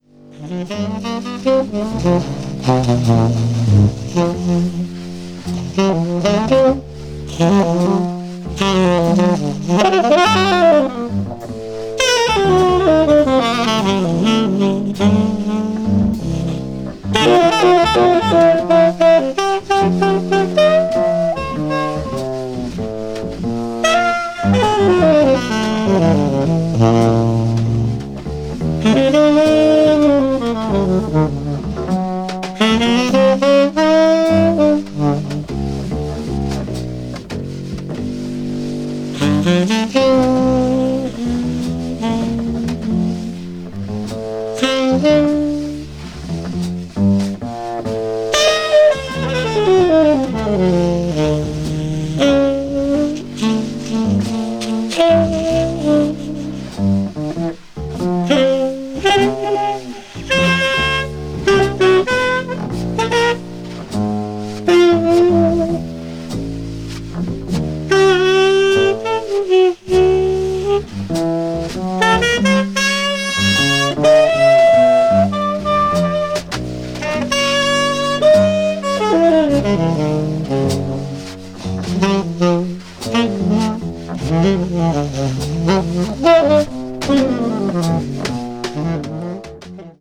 avant-jazz   free jazz   post bop   spiritual jazz